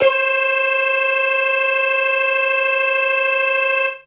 55bg-syn19-c5.wav